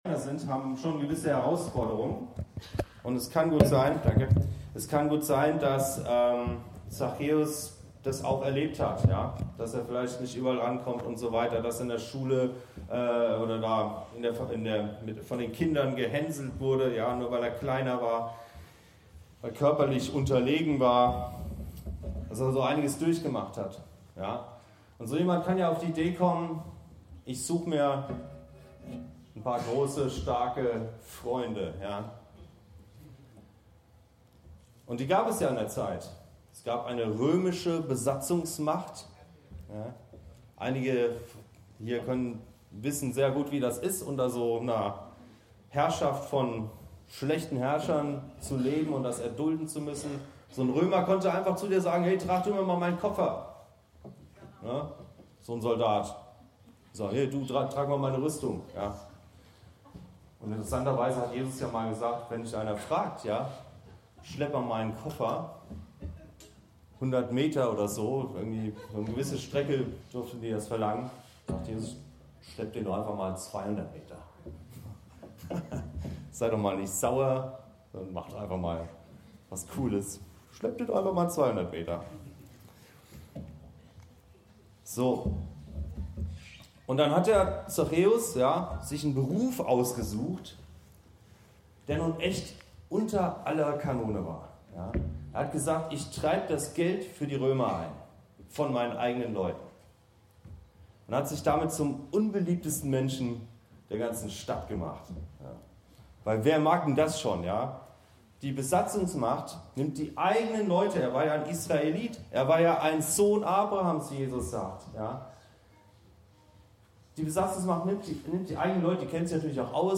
Salz und Licht- das Thema unserer Gemeindefreizeit 2023.
Predigten